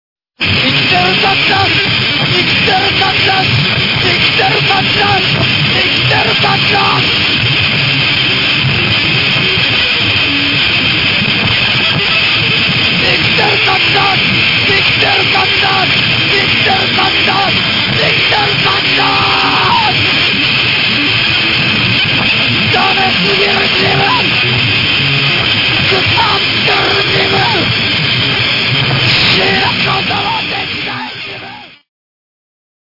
コメント ボーカル曲のみで構成された自選ベストアルバム！